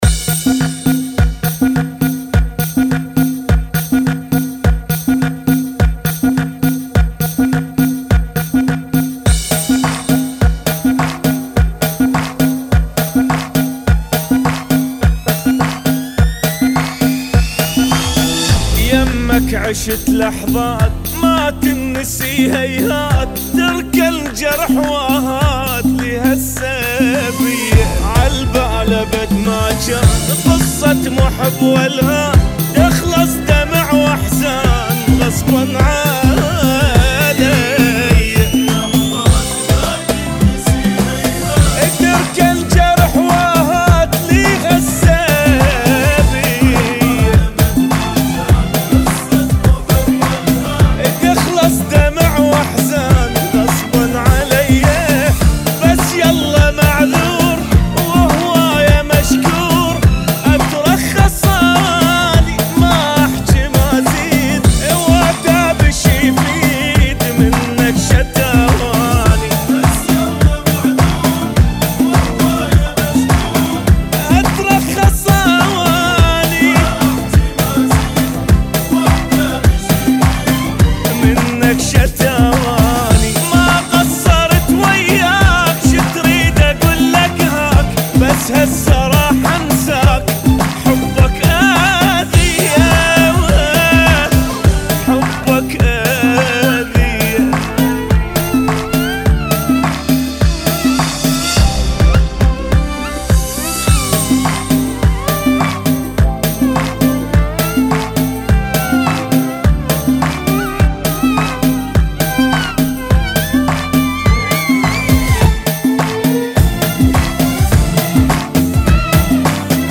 [ 115 Bpm ]